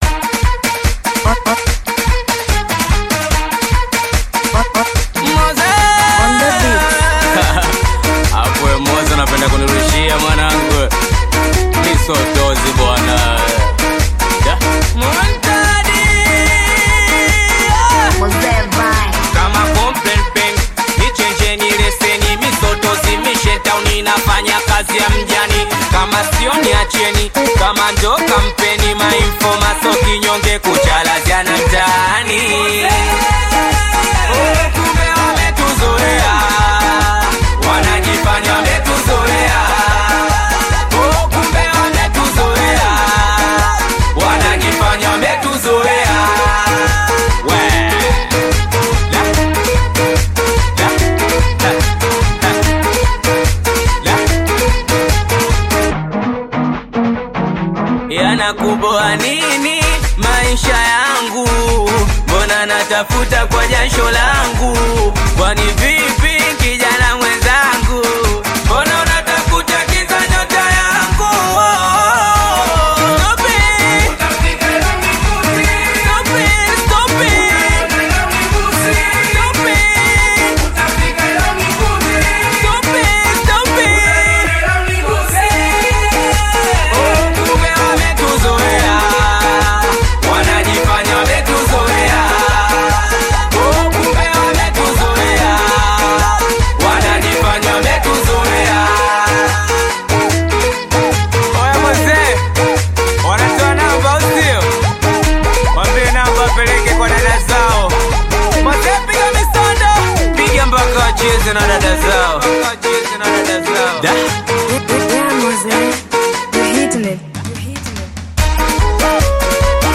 Singeli music track
Bongo Flava Singeli